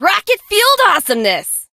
janet_start_vo_01.ogg